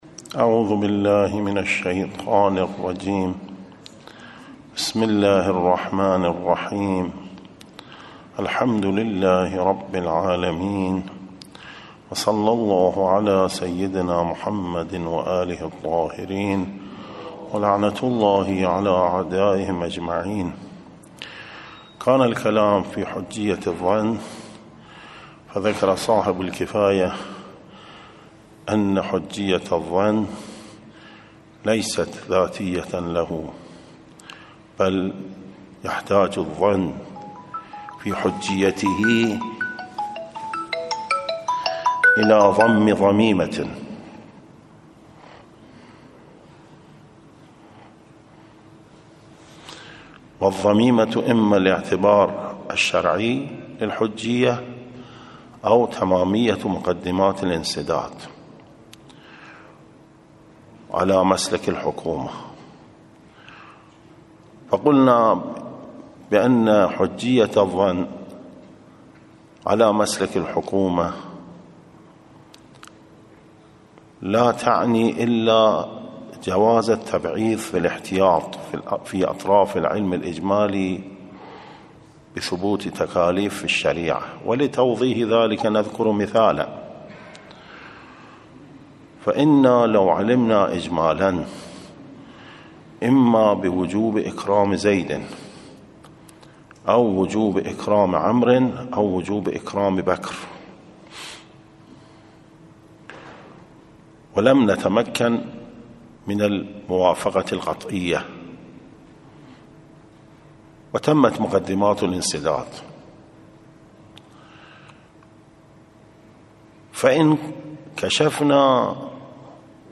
نصّ الدّرس 53 ، السبت 1 رجب المرجب 1445